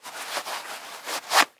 Звуки кожаных перчаток